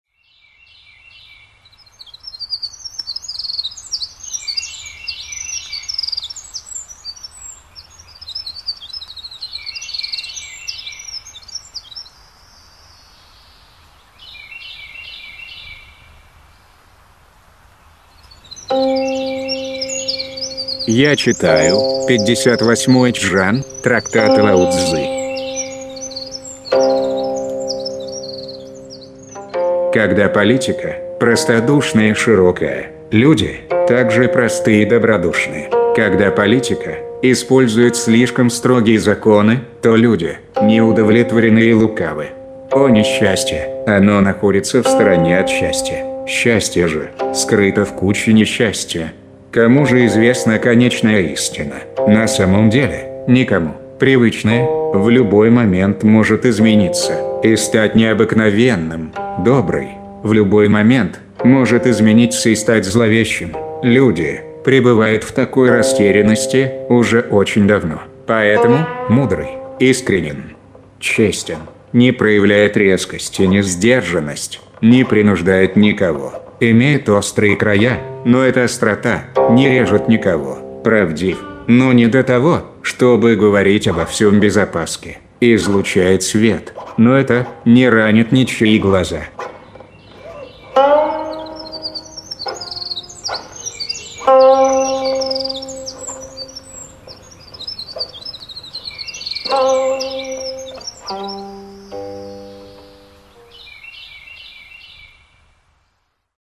Аудиокнига: Трактат о Дао и Дэ